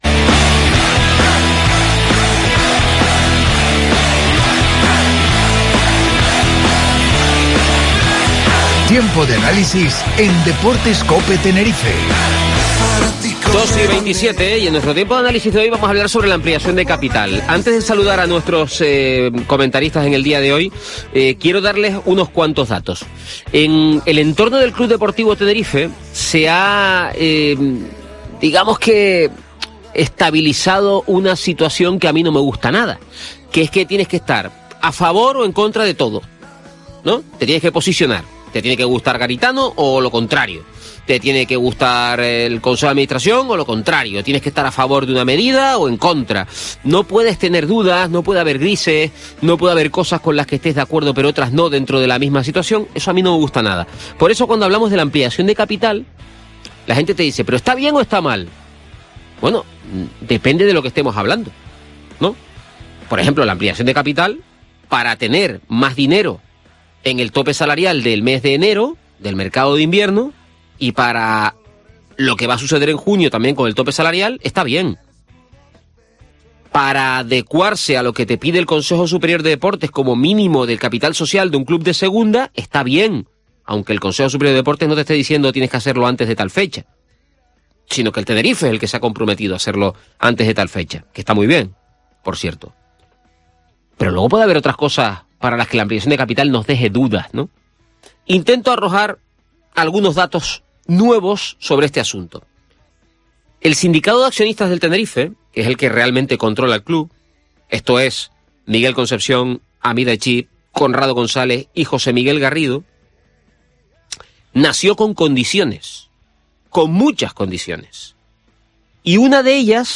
Tertulia en Deportes COPE Tenerife sobre la ampliación de capital